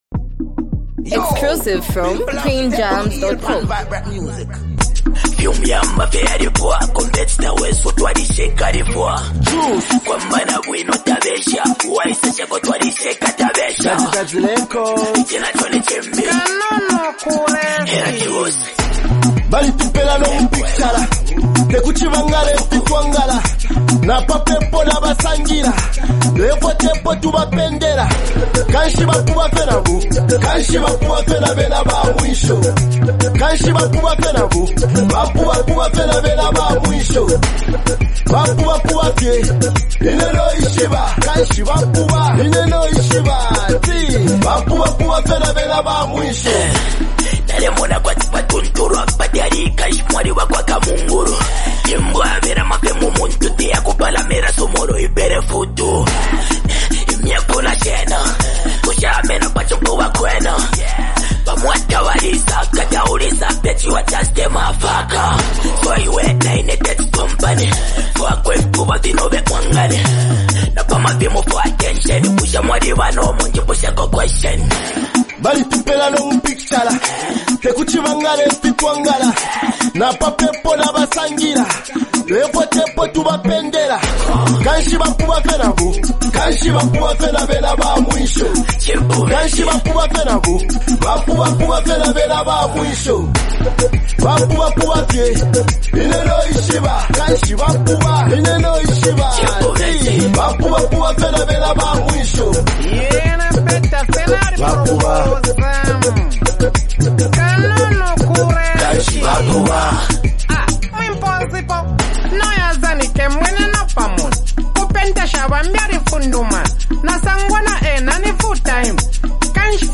vibrant, street-inspired song
confident delivery
With its danceable beat and relatable lyrics